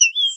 由JCG Musics于2015年创建的名为AmbiGen的装置中使用的单个鸟啁啾和短语。
Tag: 鸟鸣声 自然 现场录音